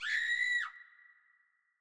Vox (MetroScream).wav